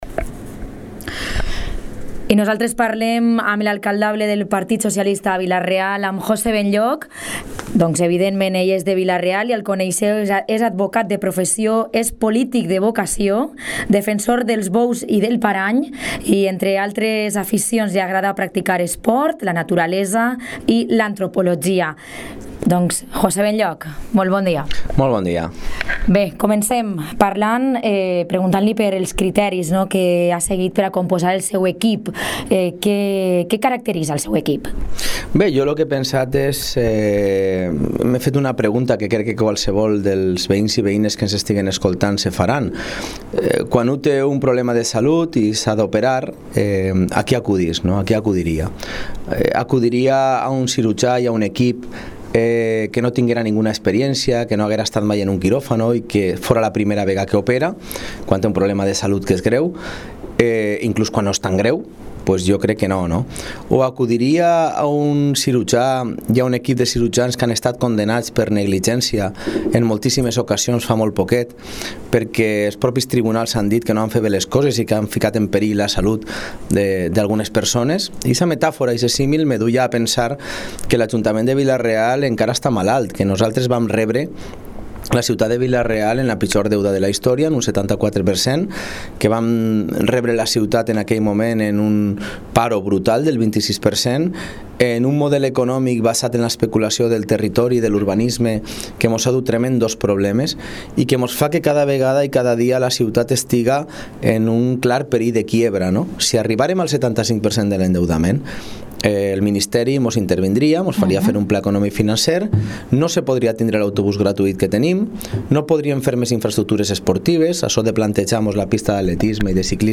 Entrevista política, PSPV